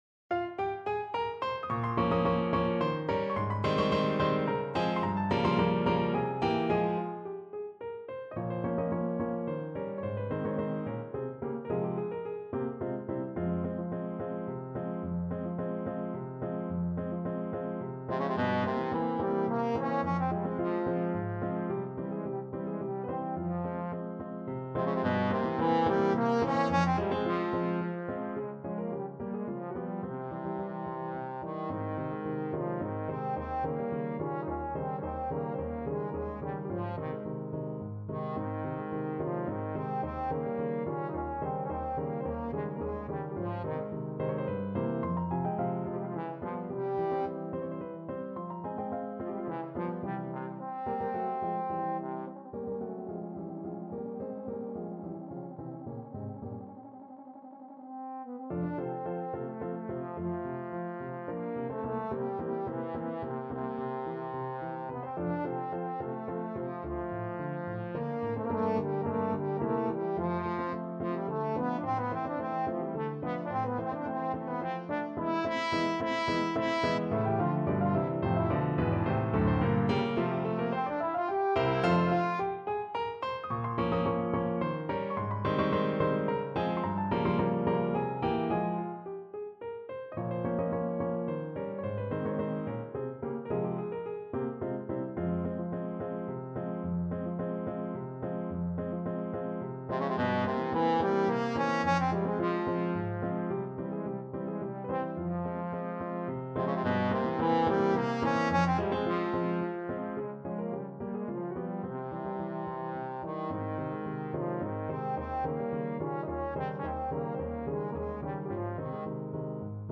Trombone version
3/4 (View more 3/4 Music)
F3-A5
~ = 100 Allegro =108 (View more music marked Allegro)
Classical (View more Classical Trombone Music)